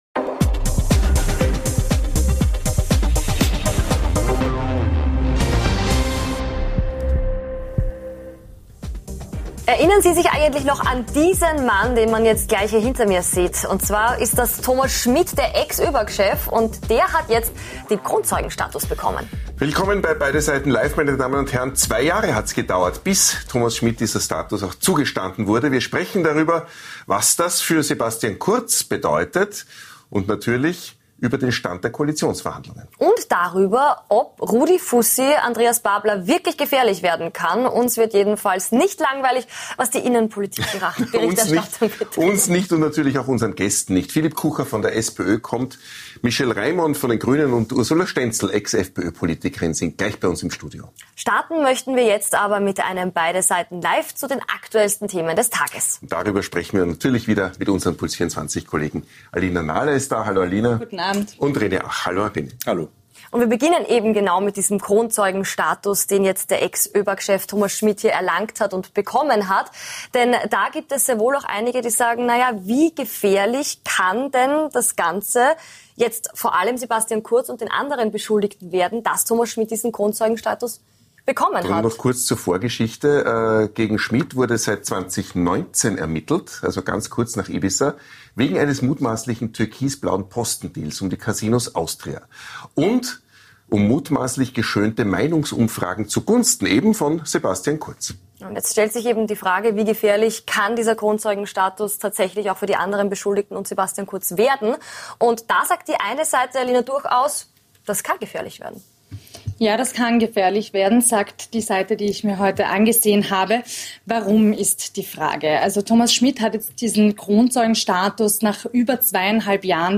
Black Friday - Schuldenfalle oder Schnäppchen-Chance? EU vor Rauchverbot - Sinvoll oder sinnlos? Und nachgefragt haben wir heute bei gleich drei Gästen - Den Auftakt mach der stv. Klubobmann der SPÖ, Philip Kucher, im Einzelinterview. Anschließend diskutieren die ehemalige FPÖ-Politikerin Ursula Stenzel und der ehemalige Grüne-Politiker Michel Reimon.